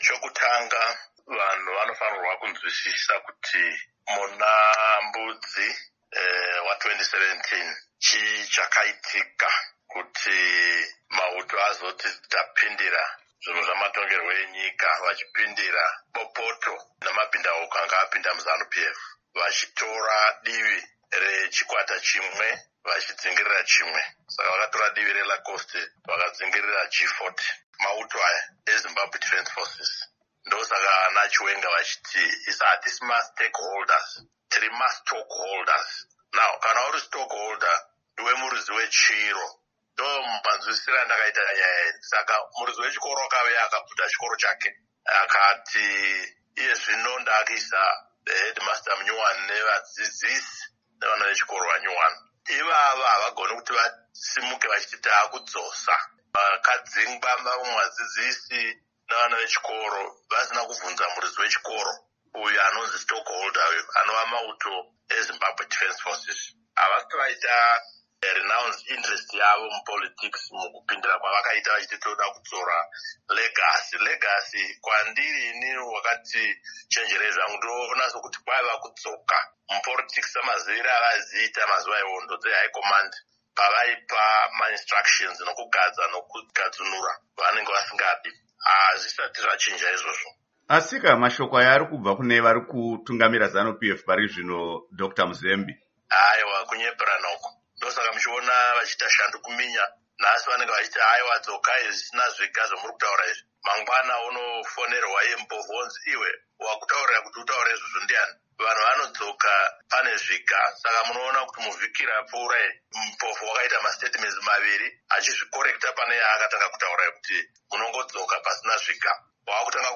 Hurukuro naDoctor Walter Mzembi